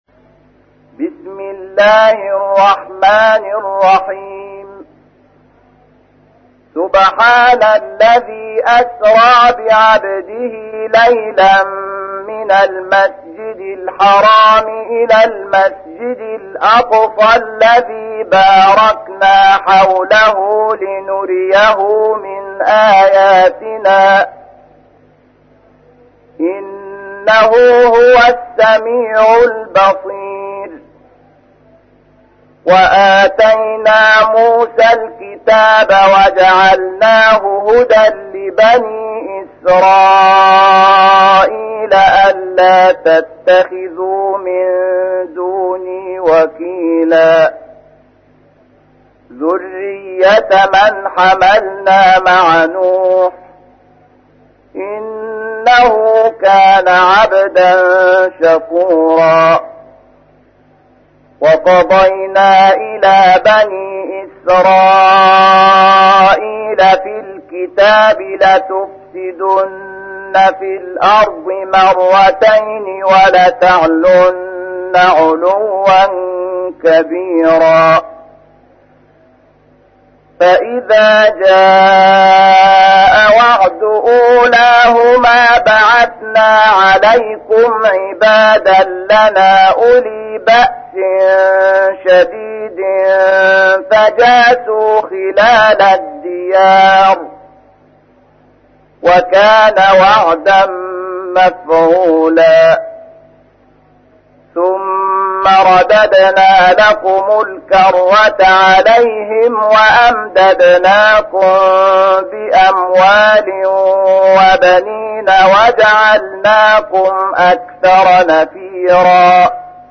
تحميل : 17. سورة الإسراء / القارئ شحات محمد انور / القرآن الكريم / موقع يا حسين